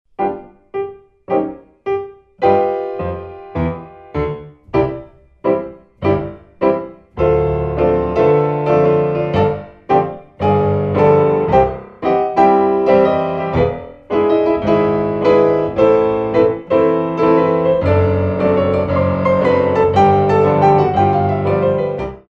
Grands Battements